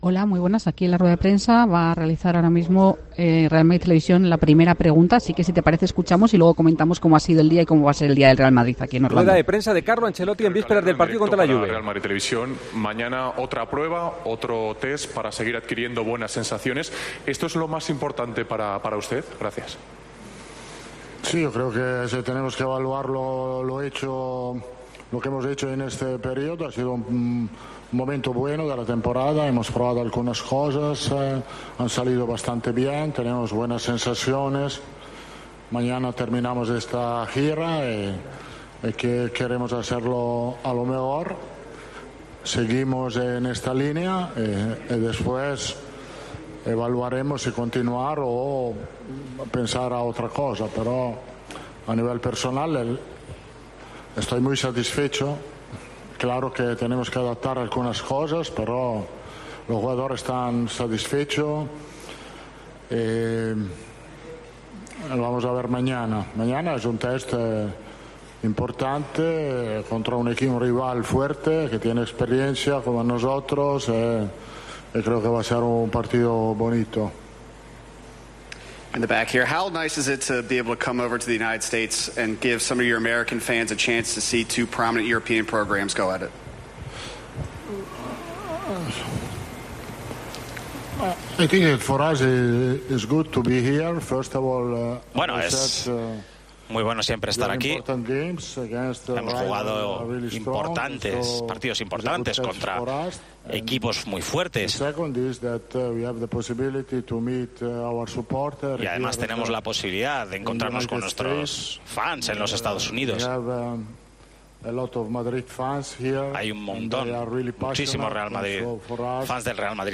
Carlo Ancelotti reflexiona en rueda de prensa sobre el papel de Vini "por fuera y por dentro"
El Partidazo de COPE Carlo Ancelotti reflexiona en rueda de prensa sobre el papel de Vini "por fuera y por dentro" Este martes, escuchamos en El Partidazo de COPE la rueda de prensa de Carlo Ancelotti. El técnico del Real Madrid reflexionó sobre Vini y volvió a pasar de puntillas sobre Mbappé.